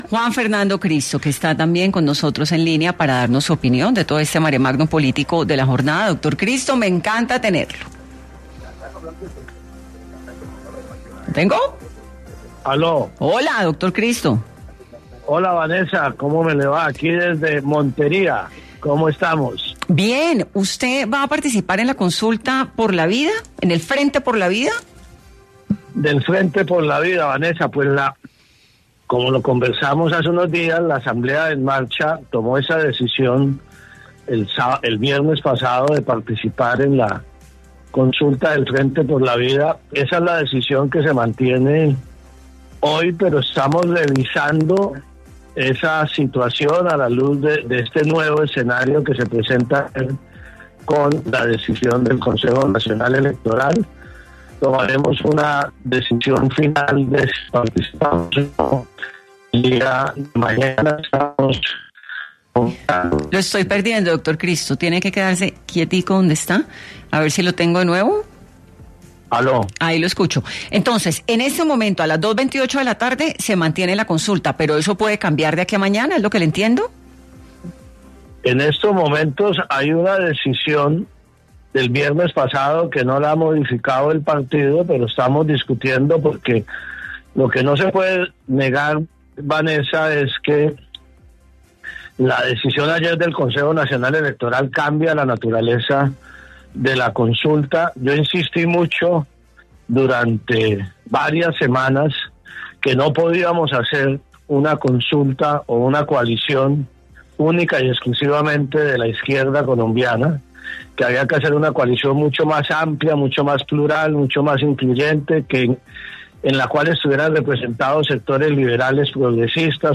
En entrevista con Caracol Radio, Cristo confirmó que, por ahora, se mantiene la decisión adoptada por su partido el pasado viernes de participar en la consulta, pero advirtió que esta podría modificarse en las próximas horas.